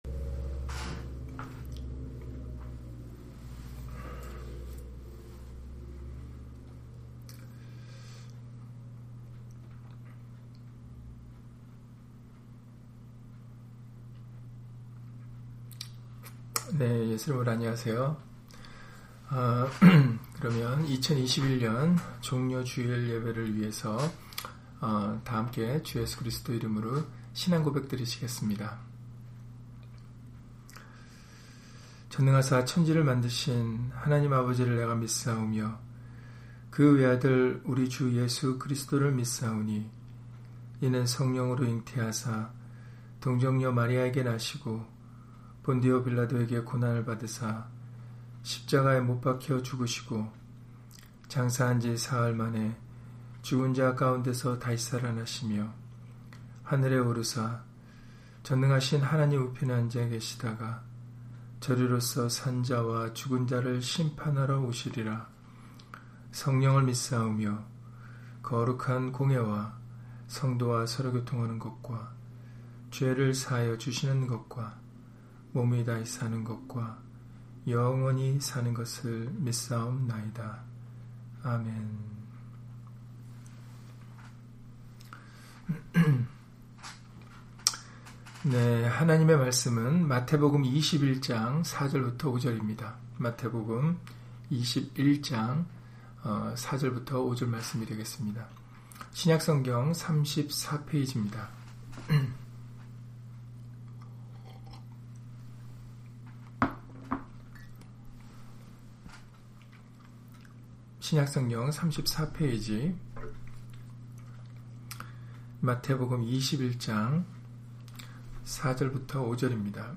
마태복음 21장 4-5절 [종려 주일] - 주일/수요예배 설교 - 주 예수 그리스도 이름 예배당